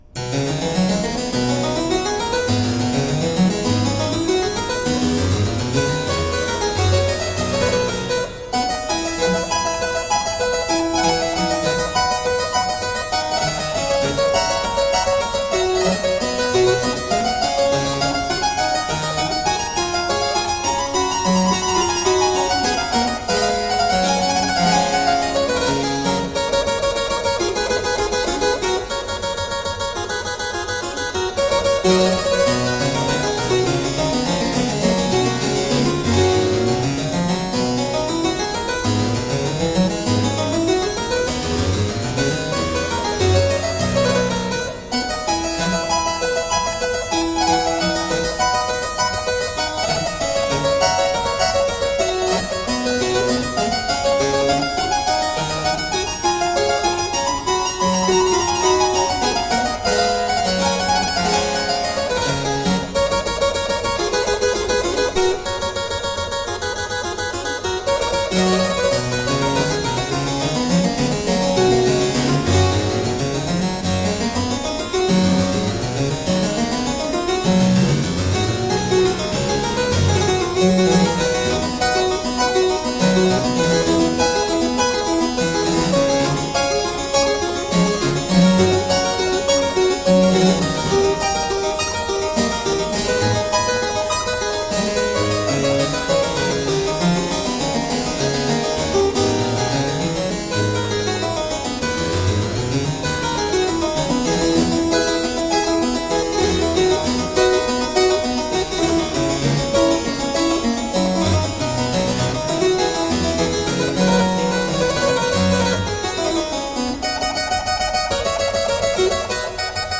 Crisp, dynamic harpsichord.